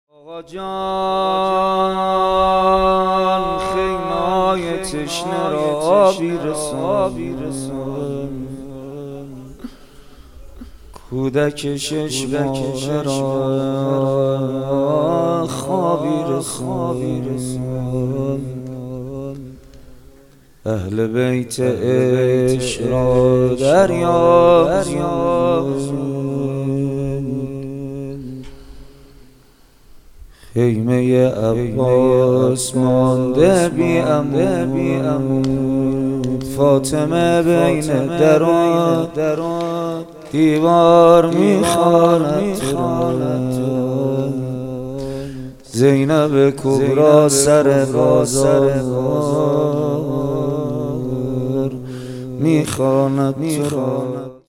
مداحی
محرم 1399 هیئت ریحانه النبی تهران